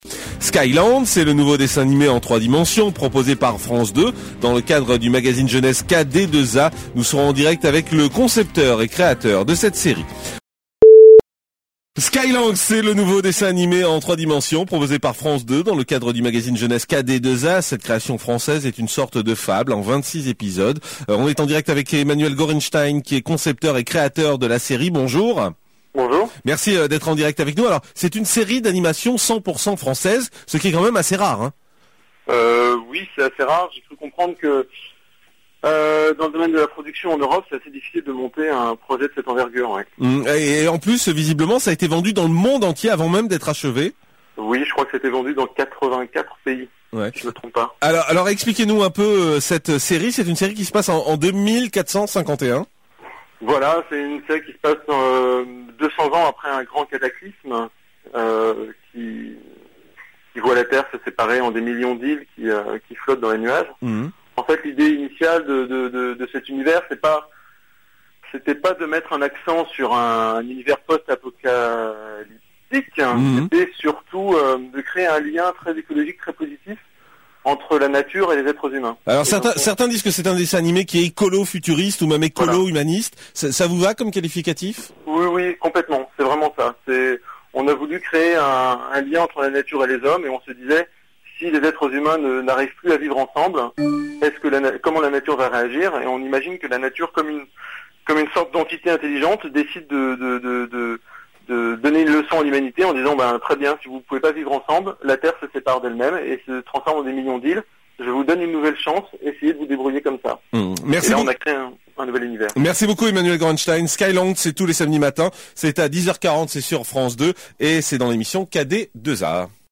Interview radio Europe 1
skyinterview.mp3